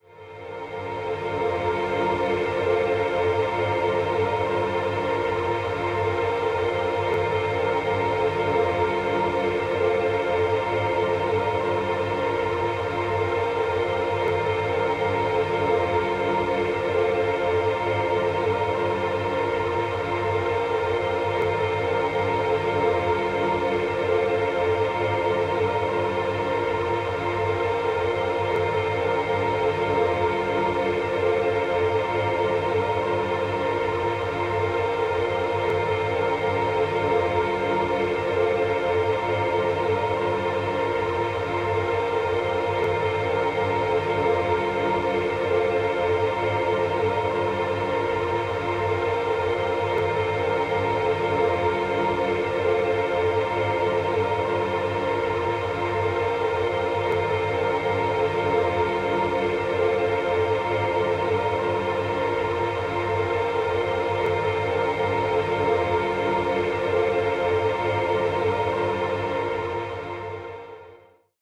Type BGM